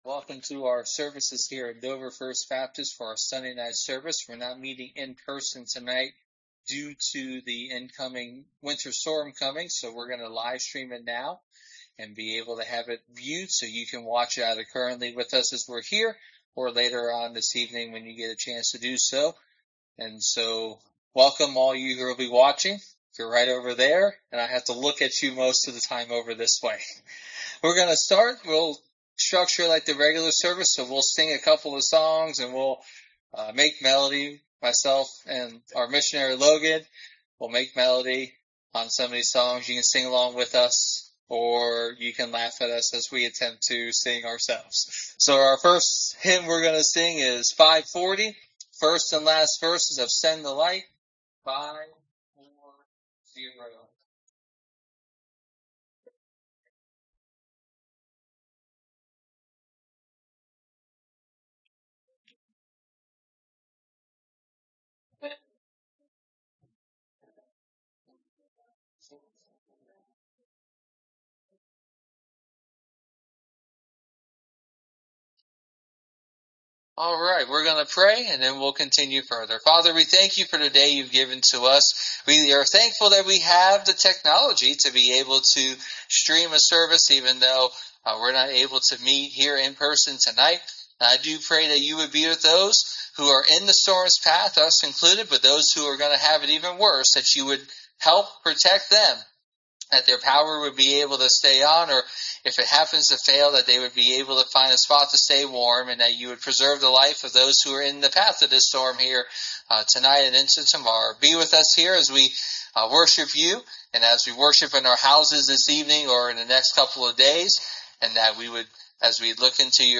Exposition of Hebrews Passage: Hebrews 11:37 - 12:3 Service Type: Sunday Evening (voice only) « When I Feel All Alone Your Gift